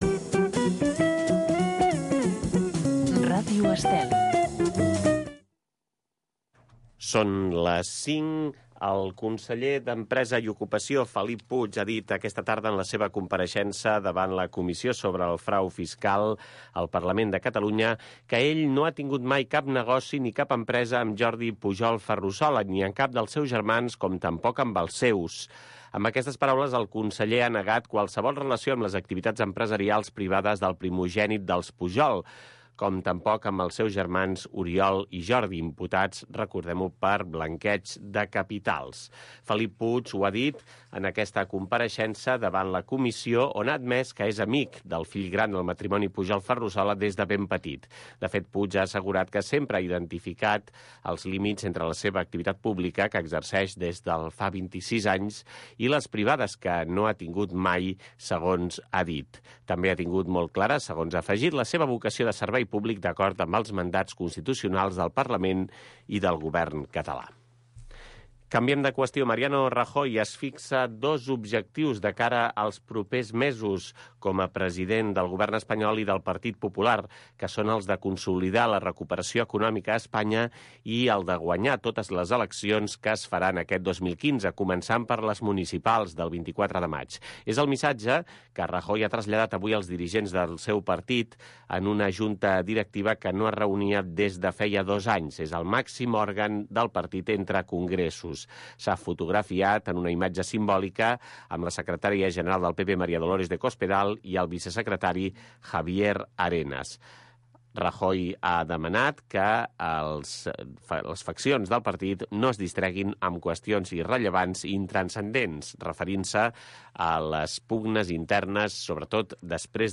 Magazín cultural de tarda.